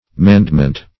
mandment - definition of mandment - synonyms, pronunciation, spelling from Free Dictionary Search Result for " mandment" : The Collaborative International Dictionary of English v.0.48: Mandment \Mand"ment\, n. Commandment.